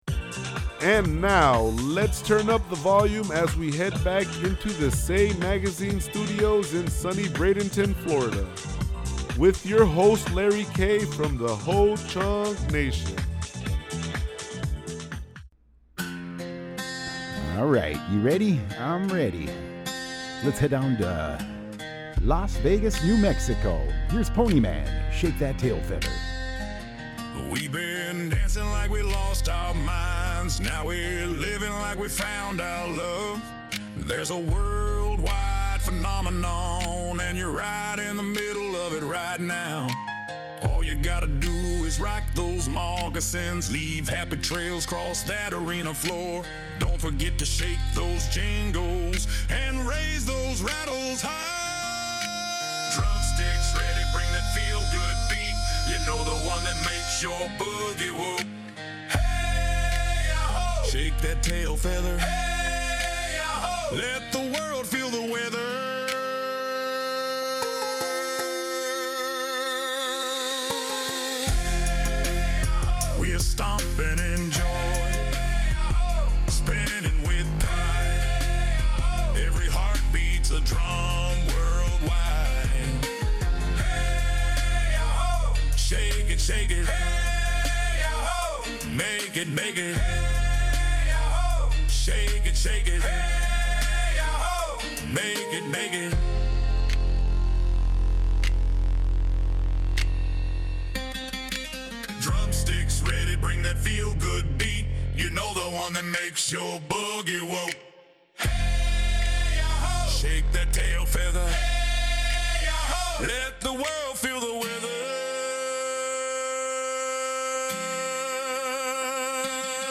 Spotlight Interview (Alternative Rock)
Program Type: Weekly Program